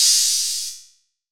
DDW2 OPEN HAT 5.wav